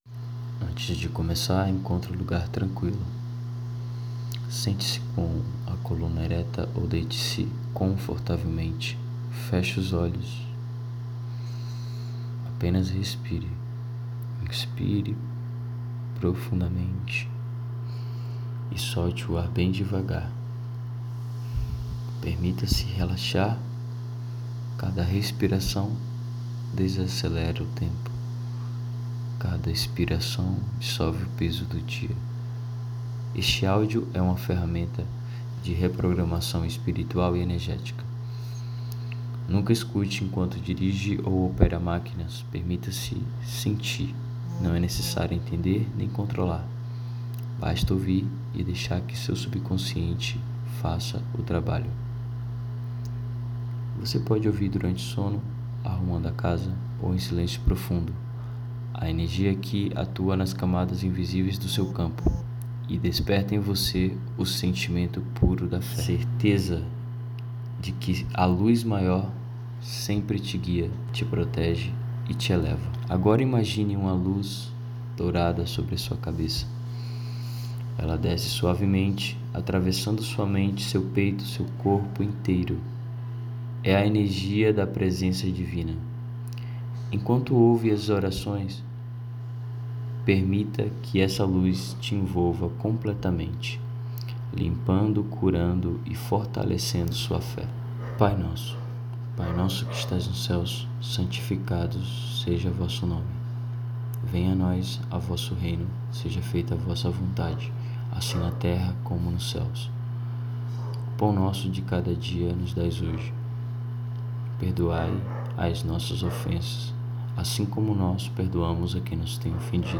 Oração Guiada